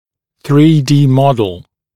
[θriː diː ‘mɔdl][3 ди: ‘модл]трехмерная модель